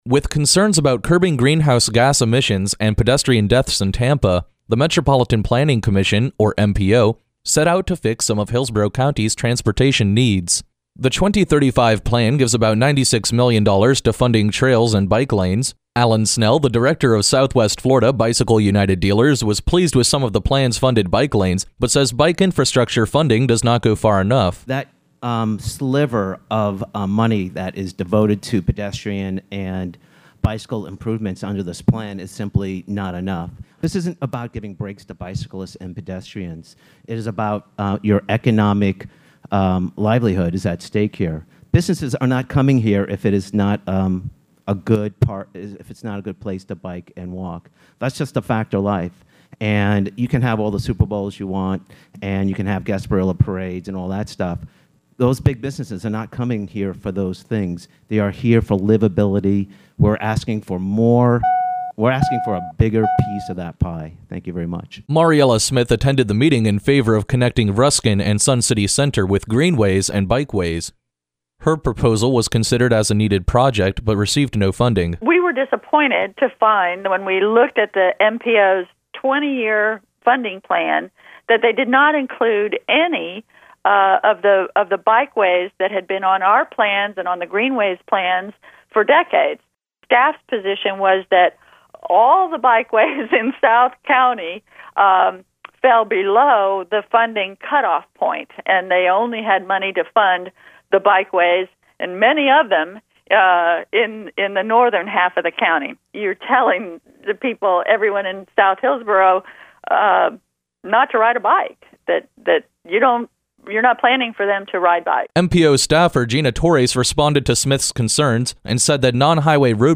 MPO 2035 Transportation Plan hearing on Wednesday, Dec. 9.